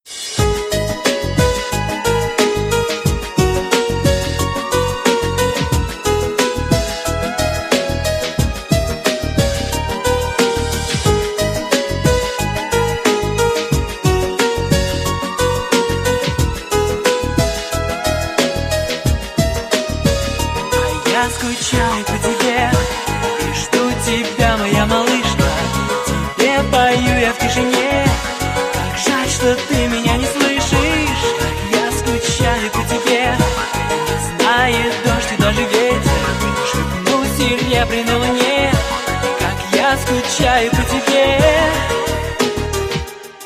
• Качество: 320, Stereo
красивые
спокойные
пианино